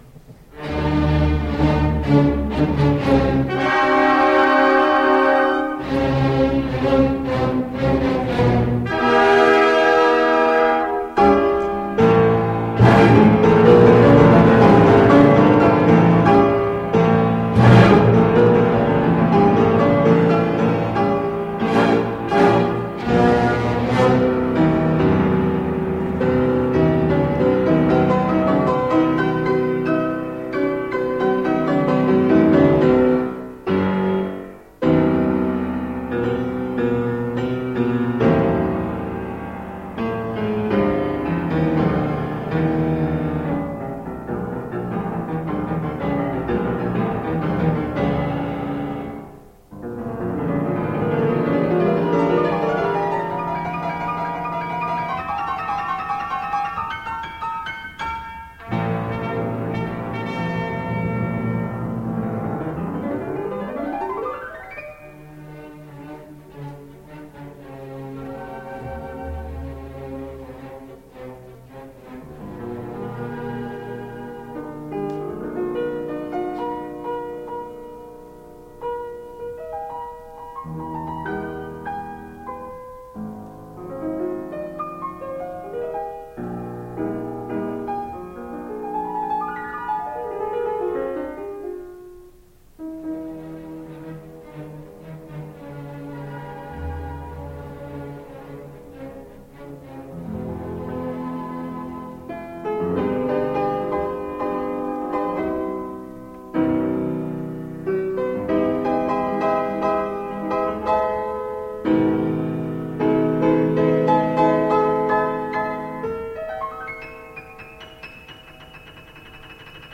Liszt, Franz - Piano Concerto No.1, S.124 Free Sheet music for Piano and Ensemble
Possibly the greatest piano virtuoso of all time, Liszt studied and played at Vienna and Paris and for most of his life toured throughout Europe giving concerts.
Style: Classical